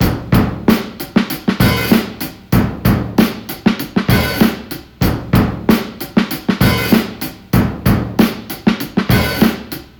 • 96 Bpm Drum Groove D# Key.wav
Free drum loop - kick tuned to the D# note. Loudest frequency: 3325Hz
96-bpm-drum-groove-d-sharp-key-iLt.wav